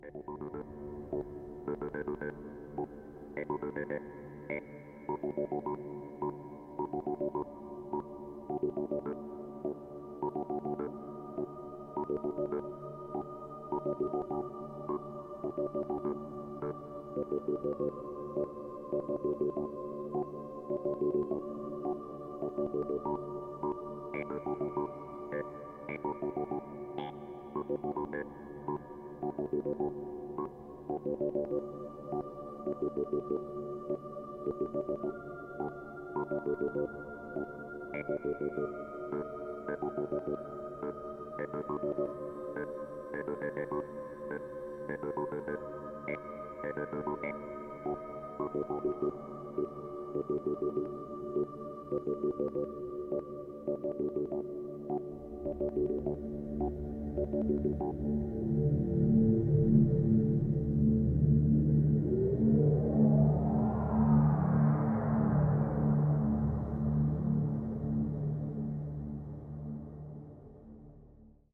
heel ontspannend, met nogal wat natuurgeluiden